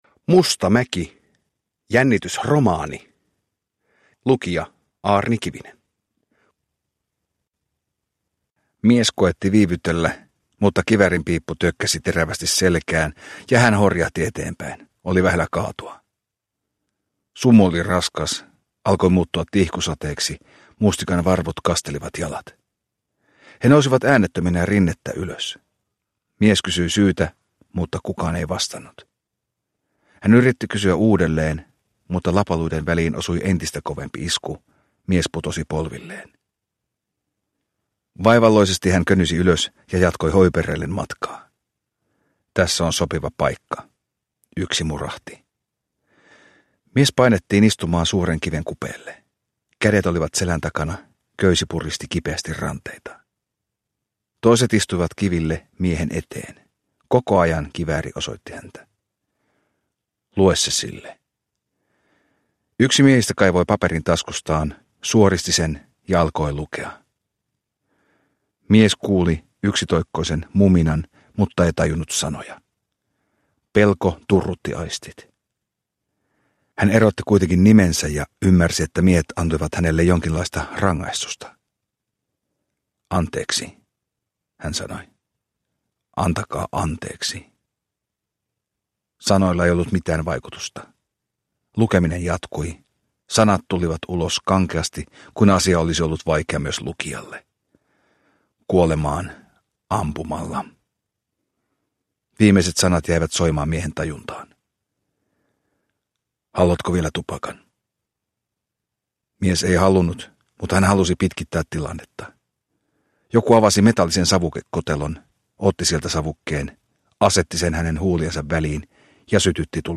Mustamäki - Jännitysromaani – Ljudbok – Laddas ner